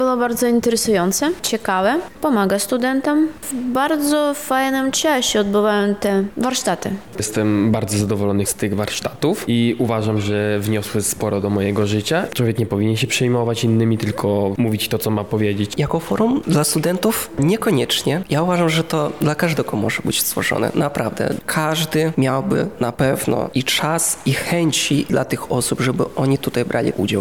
O swoich wrażeniach opowiadają uczestnicy:
SONDA